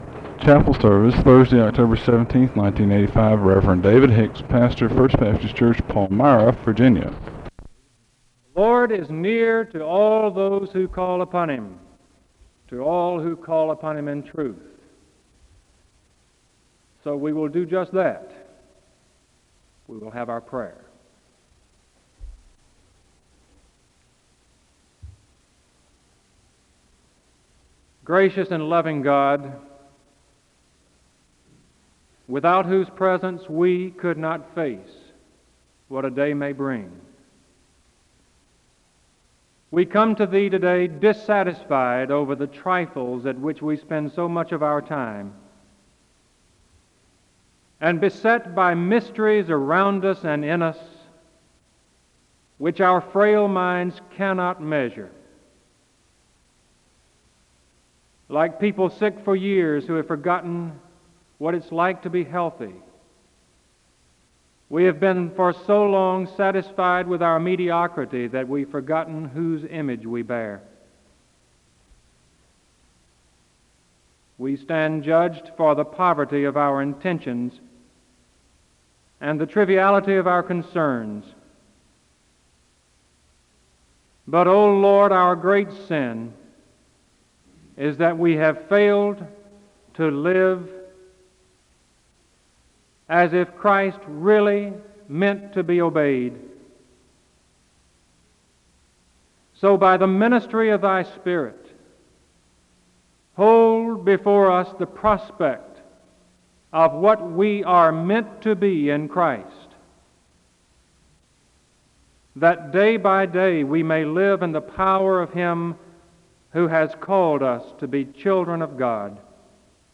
The service begins with a moment of prayer (0:00-2:08).
SEBTS Chapel and Special Event Recordings SEBTS Chapel and Special Event Recordings